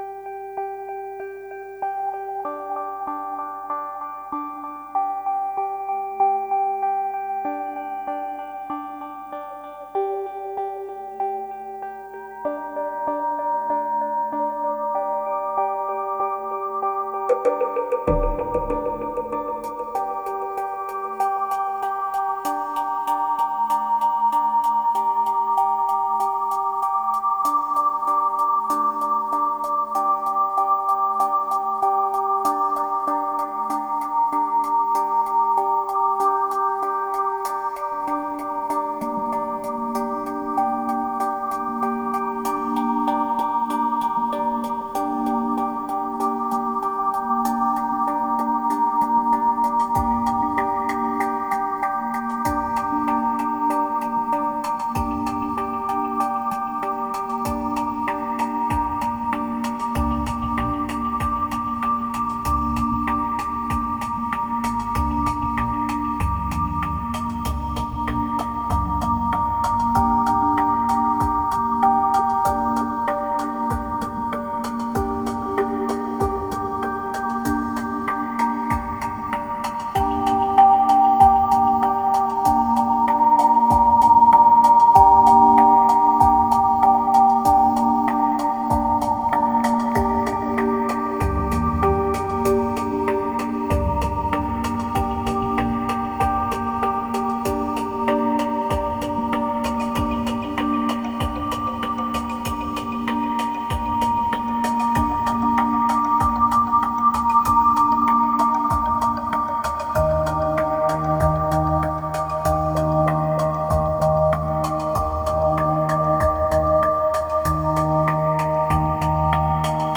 1929📈 - 64%🤔 - 96BPM🔊 - 2012-08-21📅 - 105🌟